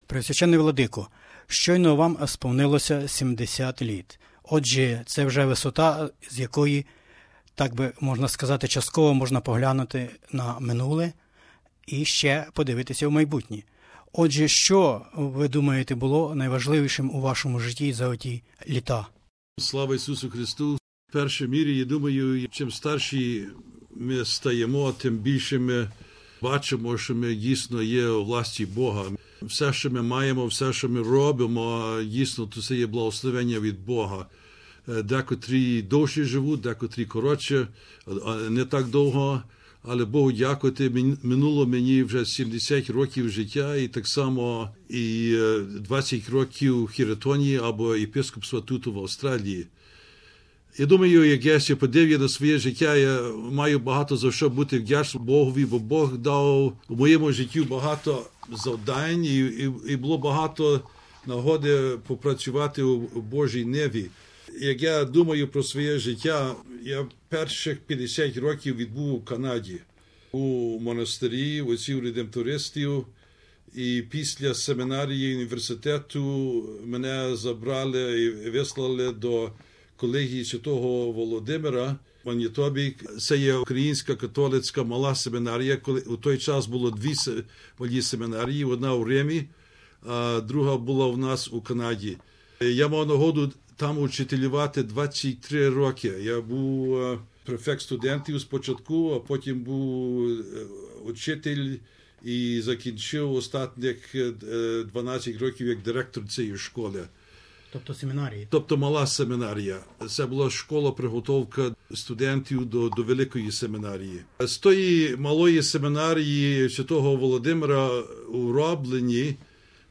веде радіоінтерв'ю із орденоносцем Австралії Преосв. Владикою Петром Стасюком із Епархії Свв. Верх. Апп. Петра і Павла Української ГрекоКатолицької Церкви в Австралії, Новій Зеландії та країнах Океанії...